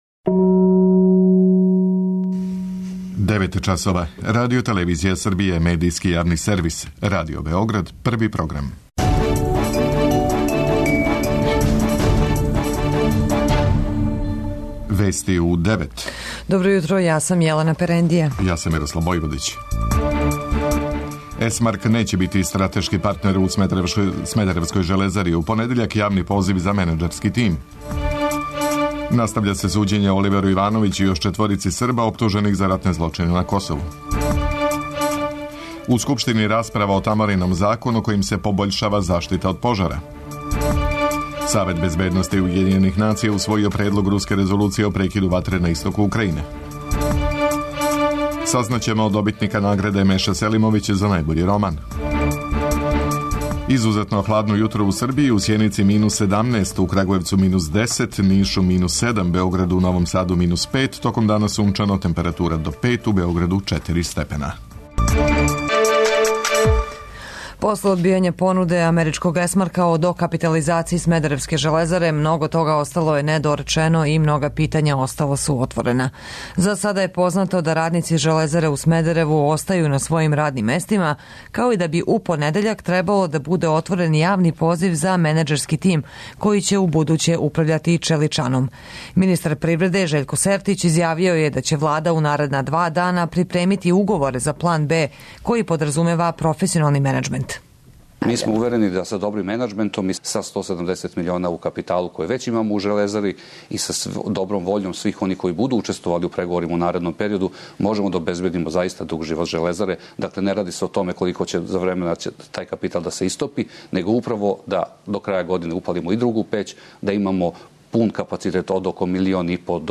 Уредници и водитељи